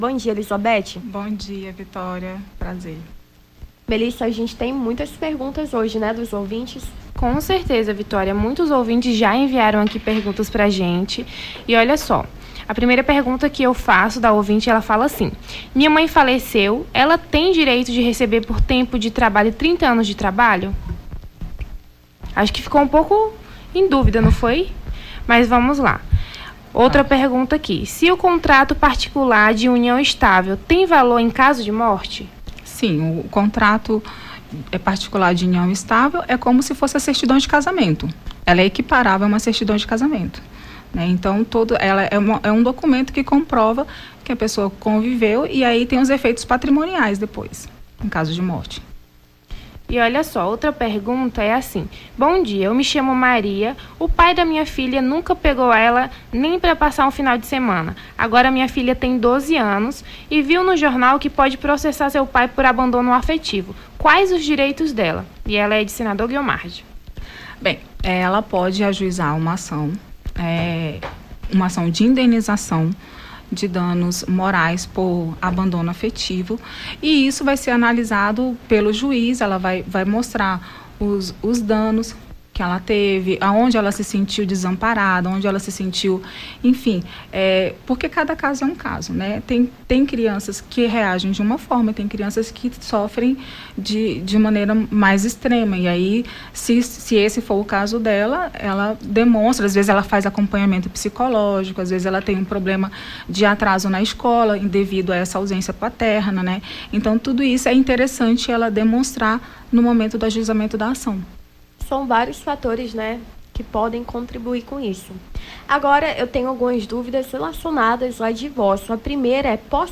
Seus direitos: Defensora Pública esclarece dúvidas dos ouvintes sobre o direito da família
as apresentadoras